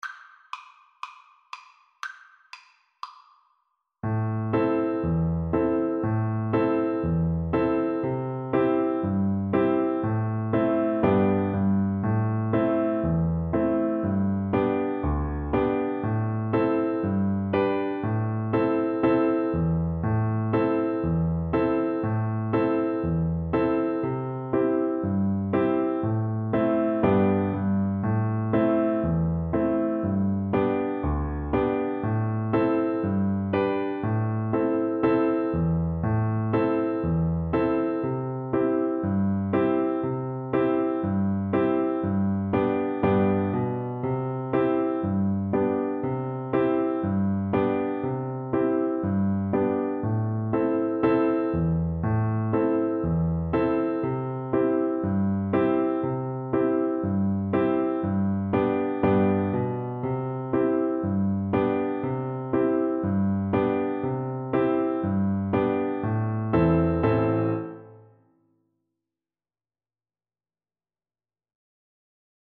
Cello
4/4 (View more 4/4 Music)
E3-C5
G major (Sounding Pitch) (View more G major Music for Cello )
Allegro (View more music marked Allegro)
Traditional (View more Traditional Cello Music)